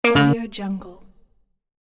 دانلود افکت صدای رابط روشن
16-Bit Stereo, 44.1 kHz